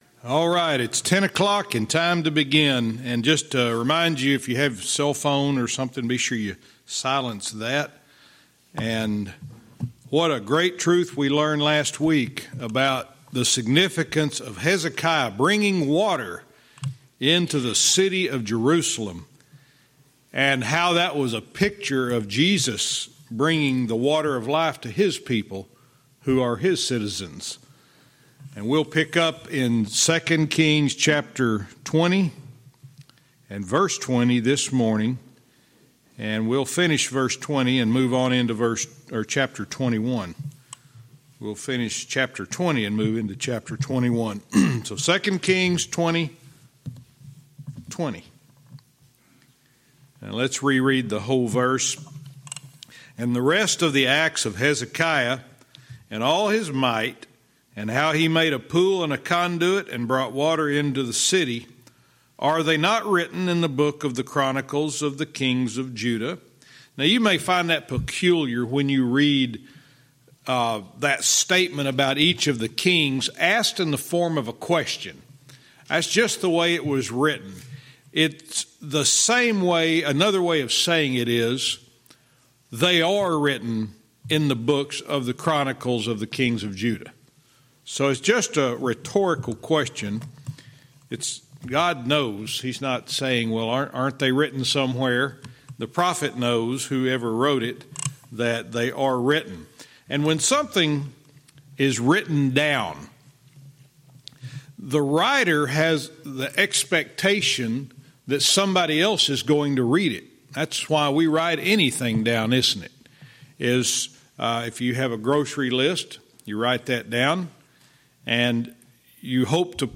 Verse by verse teaching - 2 Kings 20:20-21:4